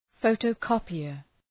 Προφορά
{‘fəʋtəʋ,kɒpıər}